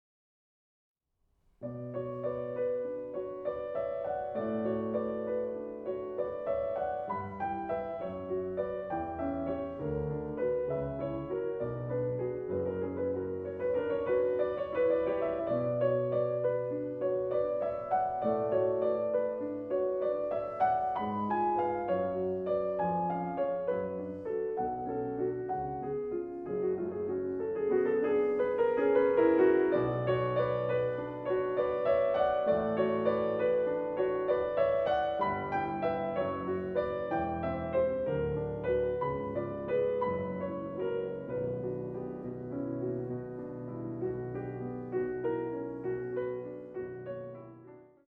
arr. for 2 pianos